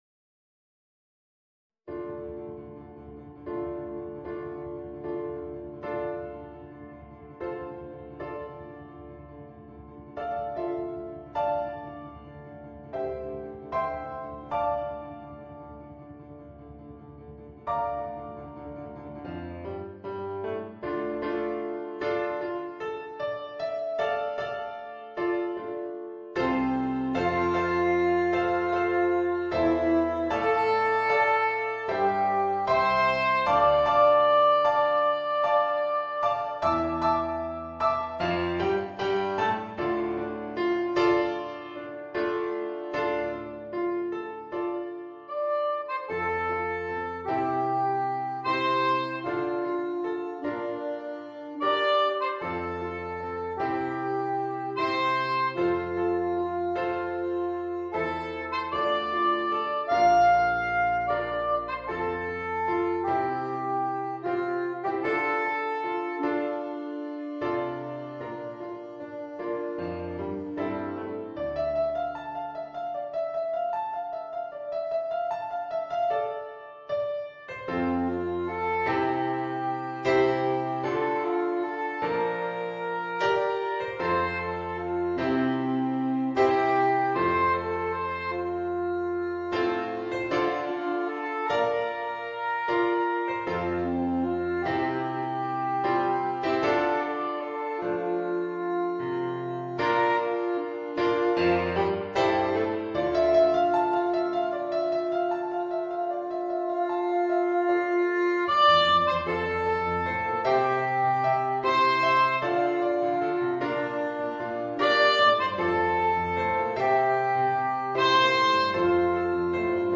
Voicing: Soprano Saxophone and Piano